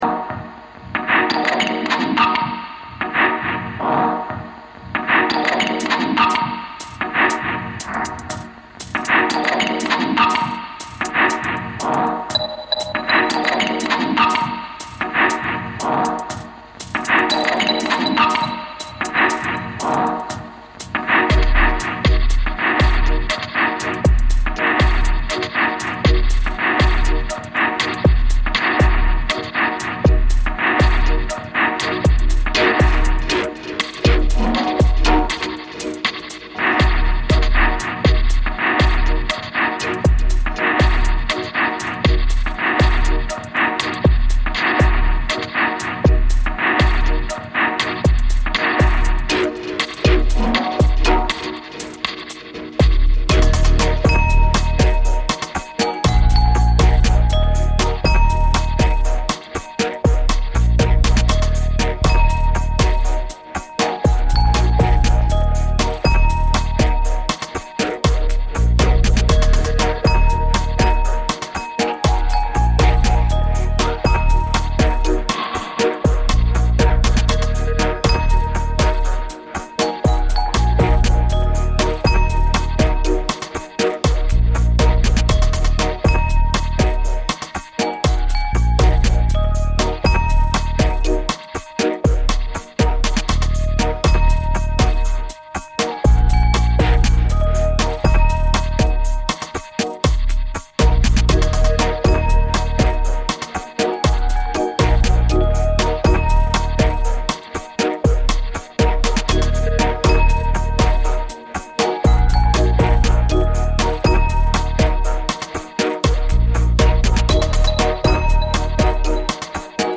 Guten Morgen und guten Tag in die Welt... Themen des heutigen Magazins